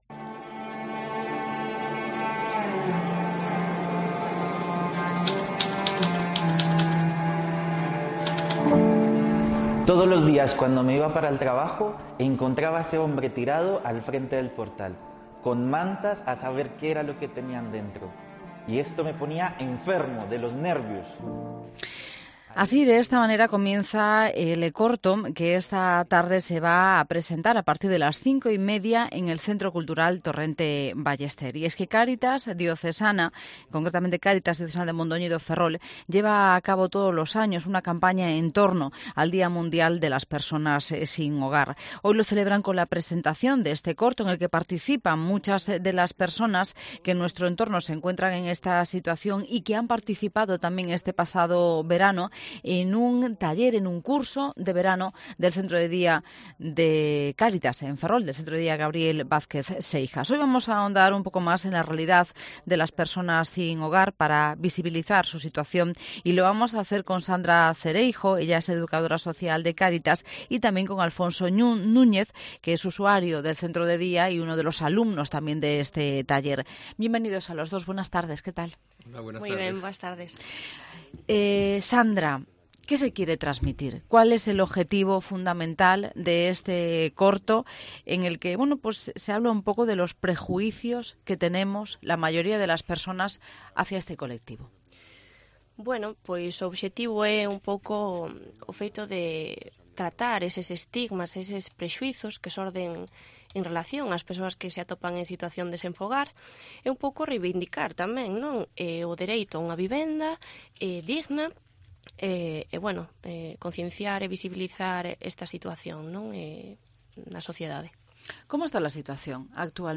estuvieron en los micrófonos de COPE Ferrol